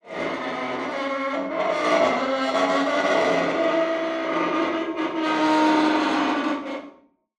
zapsplat_foley_metal_huge_scrape_against_metal_010_22862